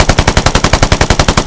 smg-mid-3.ogg